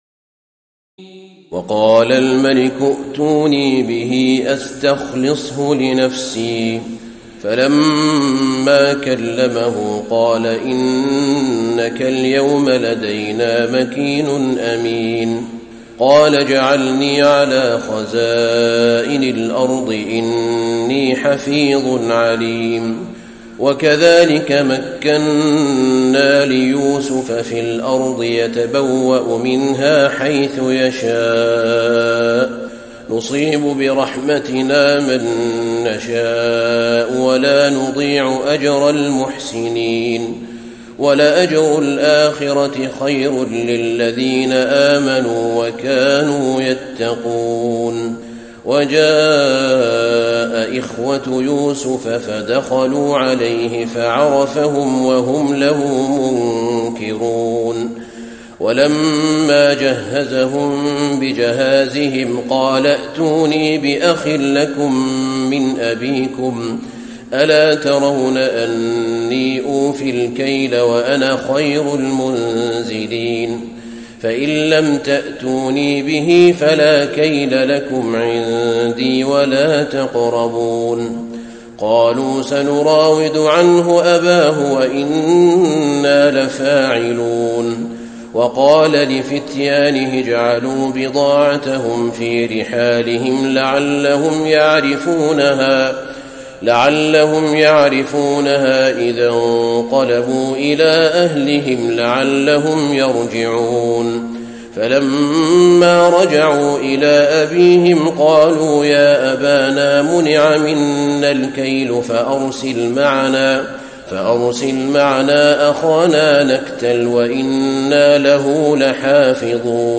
تراويح الليلة الثانية عشر رمضان 1435هـ من سورتي يوسف (54-111) و الرعد (1-18) Taraweeh 12 st night Ramadan 1435H from Surah Yusuf and Ar-Ra'd > تراويح الحرم النبوي عام 1435 🕌 > التراويح - تلاوات الحرمين